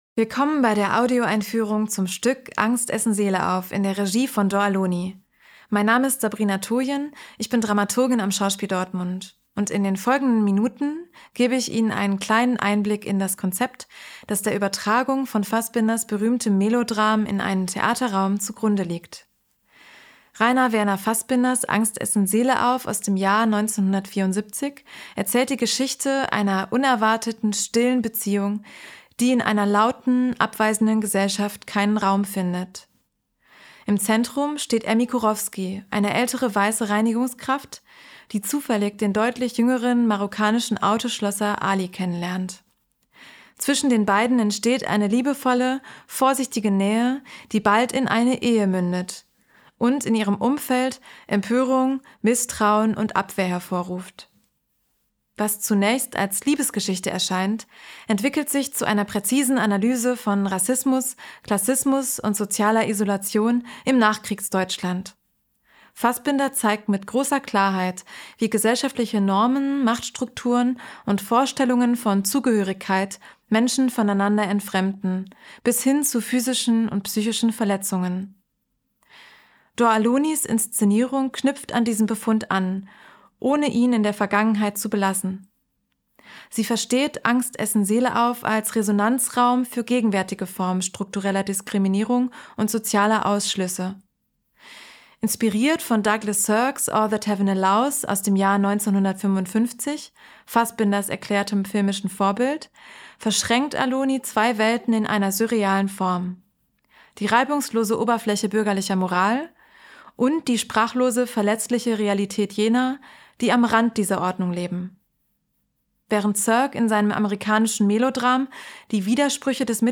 tdo_Audioeinfuehrung_Angst.mp3